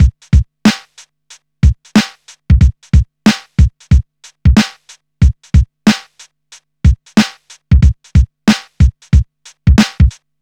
• 92 Bpm Drum Loop C# Key.wav
Free breakbeat - kick tuned to the C# note. Loudest frequency: 646Hz
92-bpm-drum-loop-c-sharp-key-MUo.wav